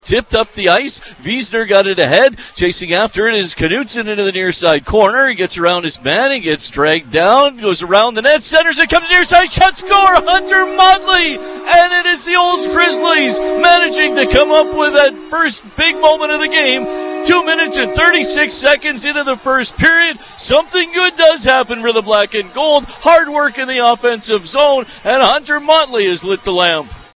As heard on 96-5 The Ranch, the Olds Grizzlys got off to a great start in front of a massive crowd on Westview Co-op bike night.